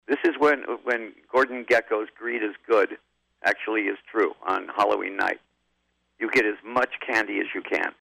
Off HALLOWEEN, INTERVIEW,